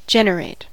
generate: Wikimedia Commons US English Pronunciations
En-us-generate.WAV